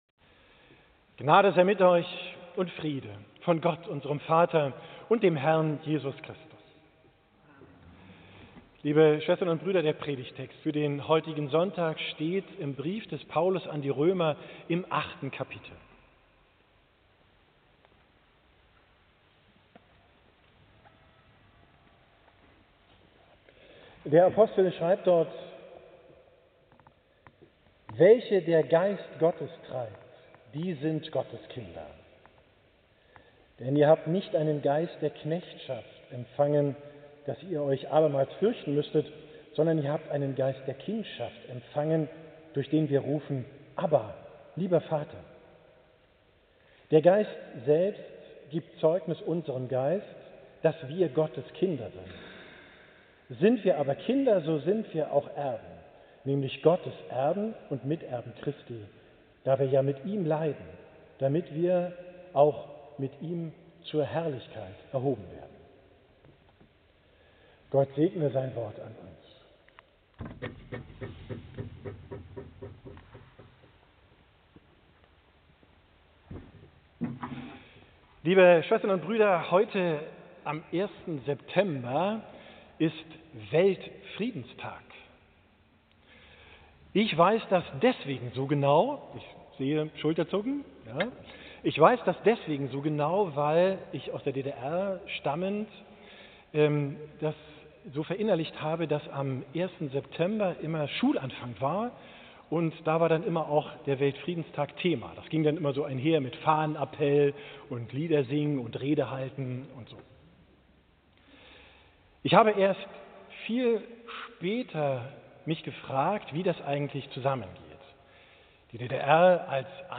Predigt vom 14.